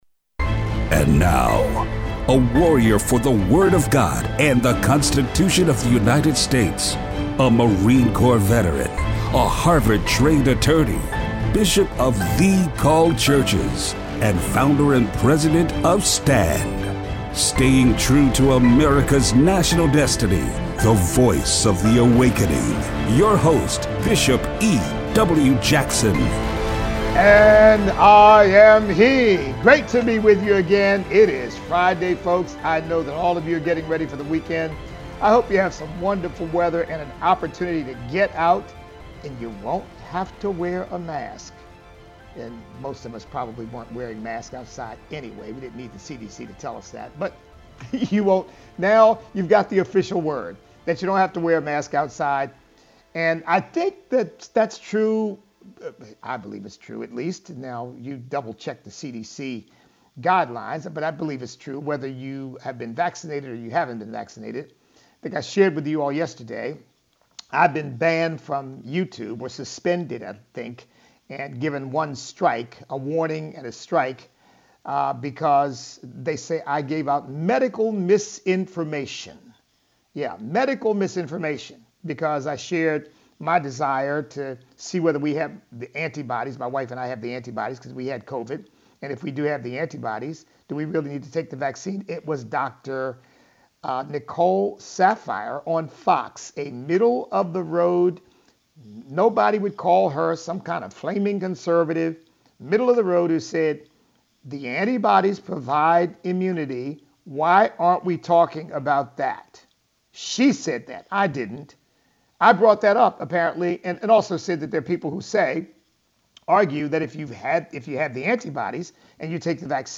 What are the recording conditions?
Open phone lines.